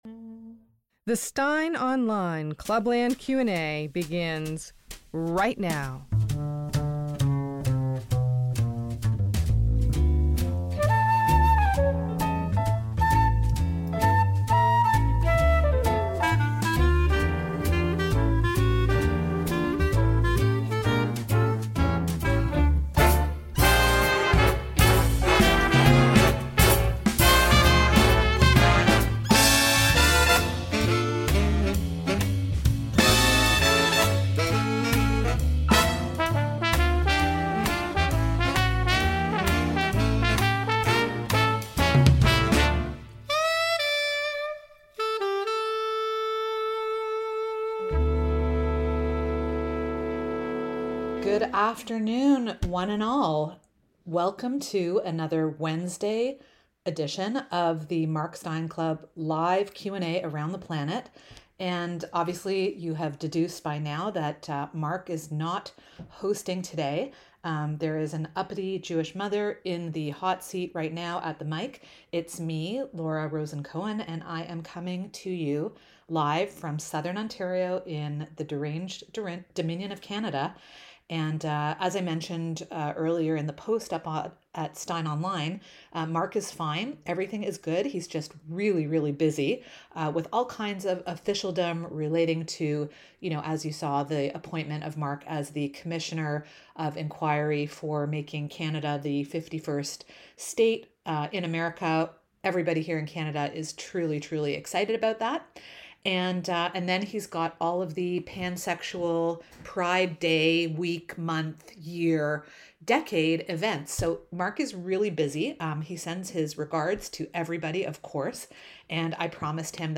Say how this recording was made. If you missed today's edition of Mark's Clubland Q&A live around the planet, you can listen to the replay by clicking above.